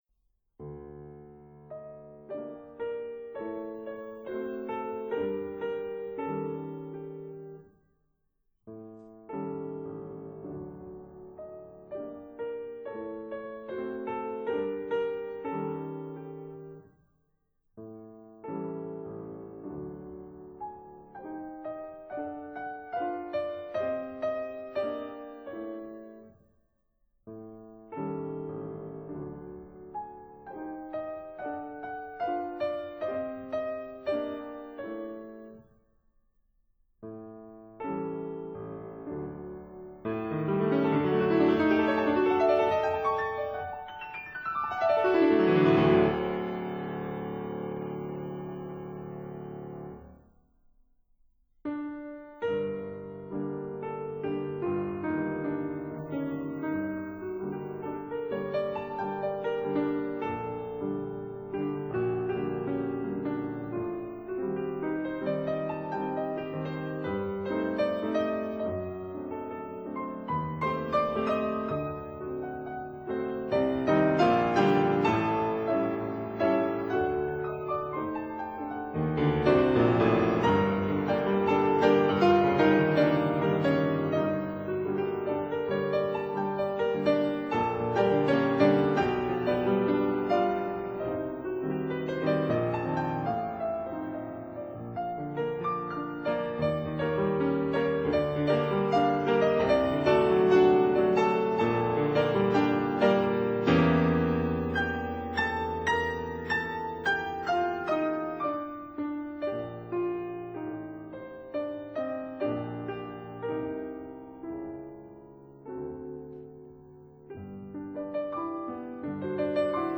piano Date